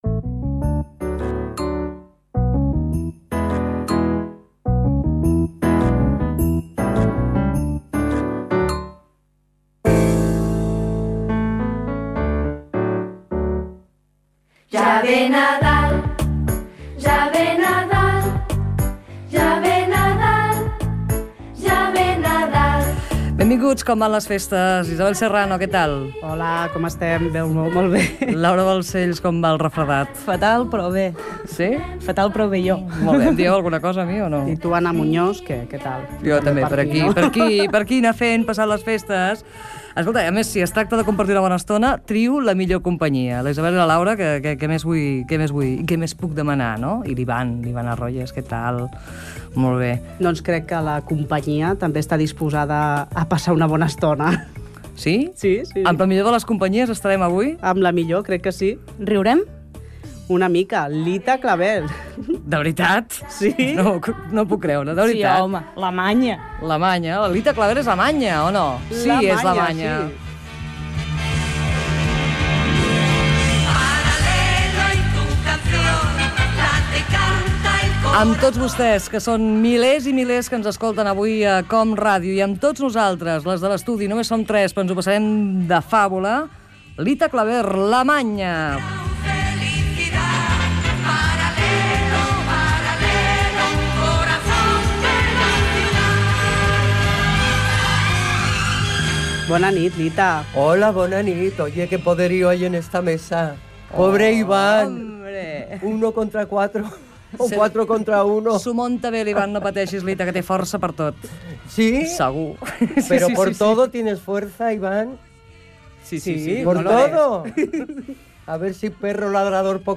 Fragment d'una entrevista a Lita Claver "La Maña".
Entreteniment